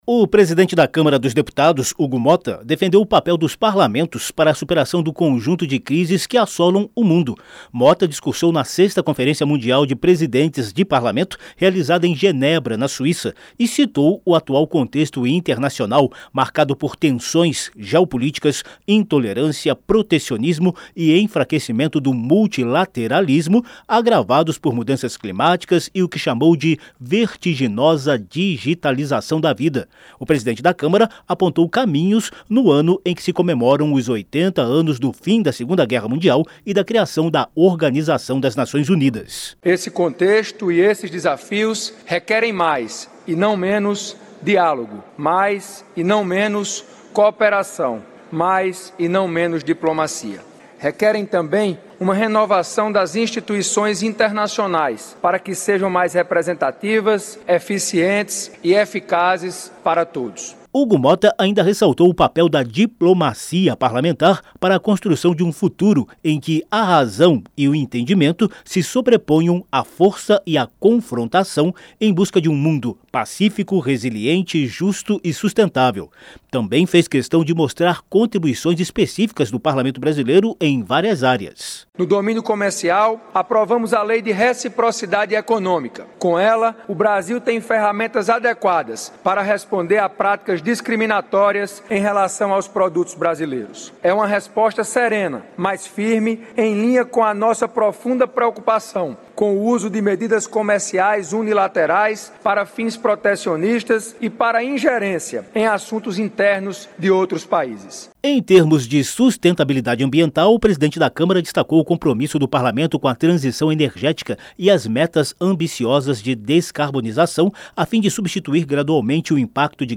COMO INFORMA O REPÓRTER